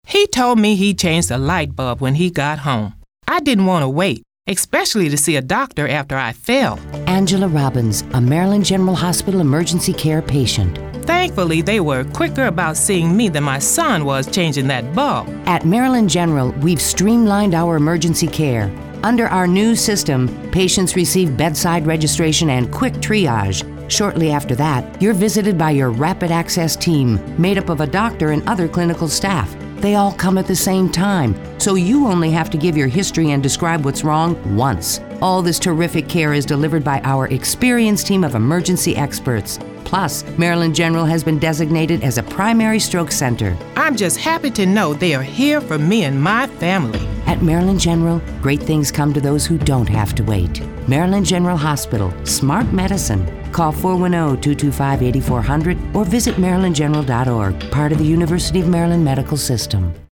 Voiceovers
– Female
– Adult
Southern American
Calming
I use a RODE NT 1 Condenser Microphone with pop filter; For additional room soundproofing, I use Alctron PF8 Mic screen; Audacity recording/editing software; Focusrite 2i2 Interface; 2020 HP Pavilion Laptop/300 MBPs; and headphones are Audio Technics.
MarylandGenHospital-VO.mp3